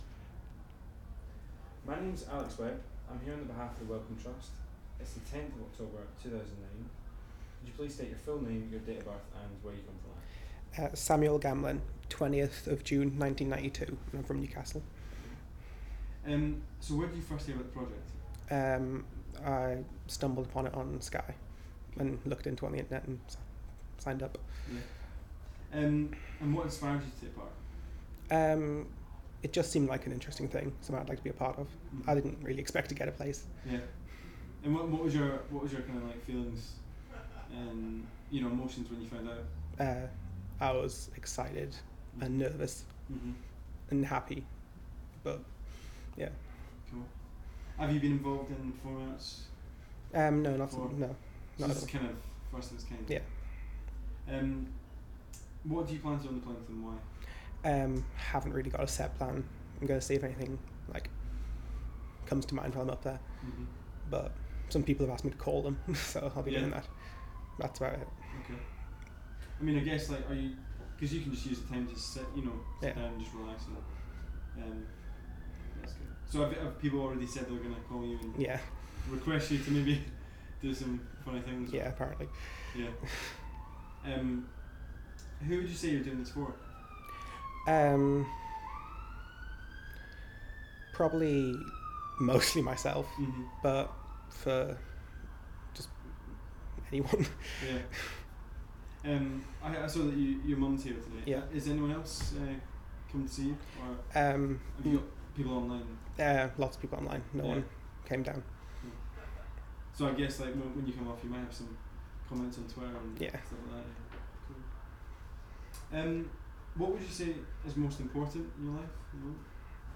Audio file duration: 00:06:04 Format of original recording: wav 44.1 khz 16 bit ZOOM digital recorder.
These recordings are part of the One & Other interview series that has been licensed by the Wellcome Trust for public use under Creative Commons Attribution-non commercial-Share Alike 3.00 UK.